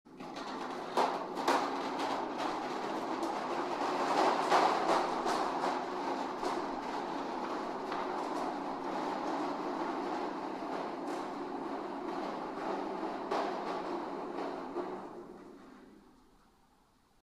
ガラガラ音